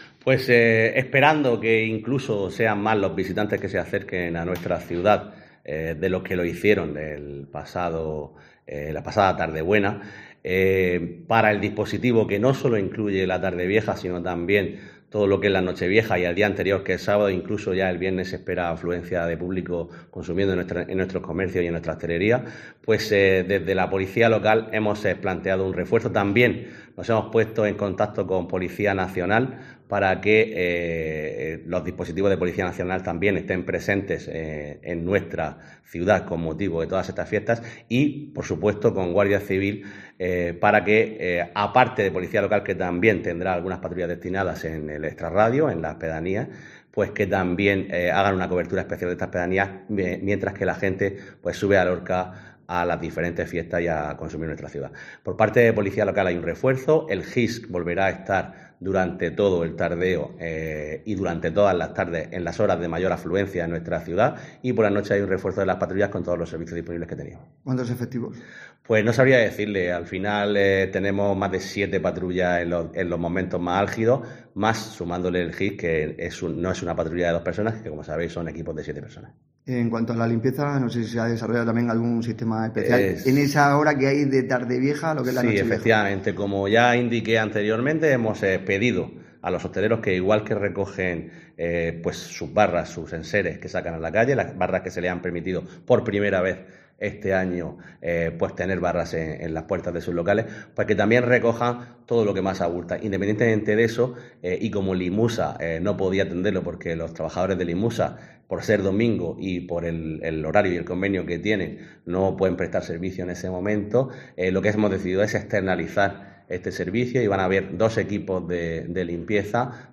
Juan Miguel Bayonas, edil Seguridad Ciudadana de Ayuntamiento de Lorca
Juan Miguel Bayonas ha contado en COPE, las características especiales de ese sistema especial de seguridad y limpieza para el último fin de semana del año "el dispositivo no sólo incluye Tardevieja sino también sábado y lunes".